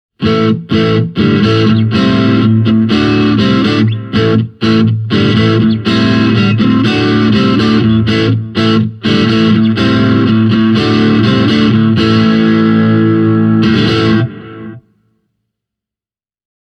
Tässä muutama esimerkkisoundi:
Blackstar ID:60TVP – Strat Clean chorus reverb